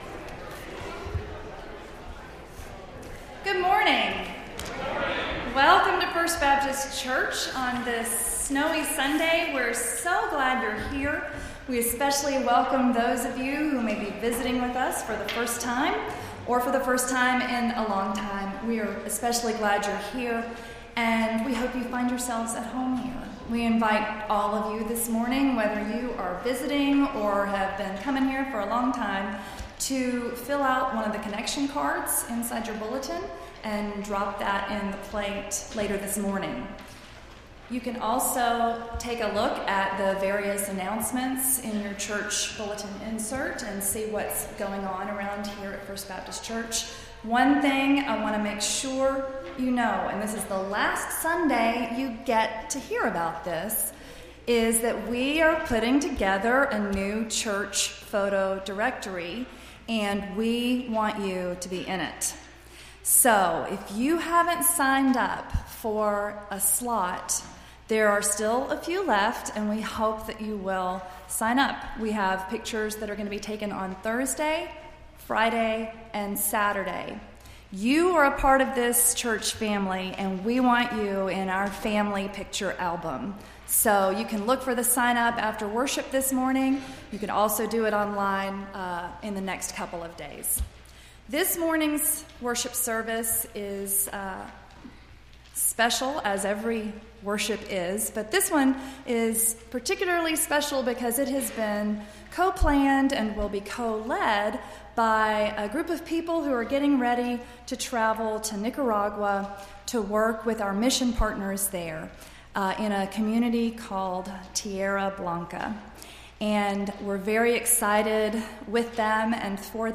Entire February 4th Service